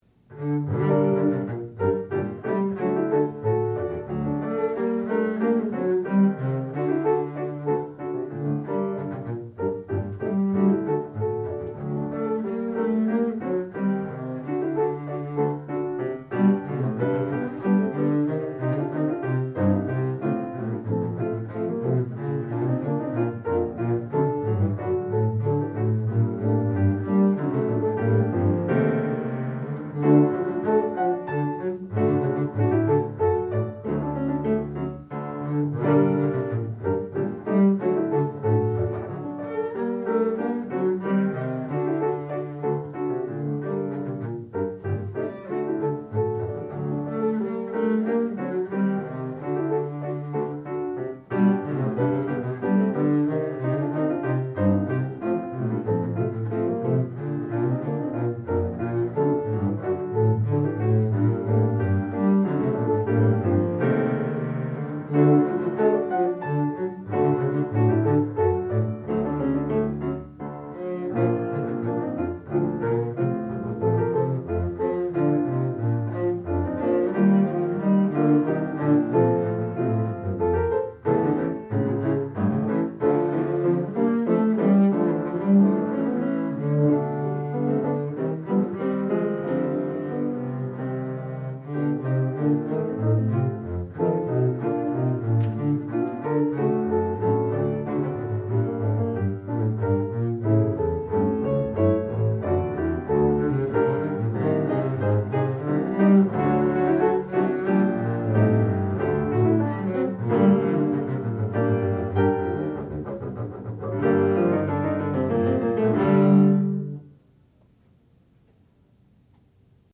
sound recording-musical
classical music
piano
double bass
Qualifying Recital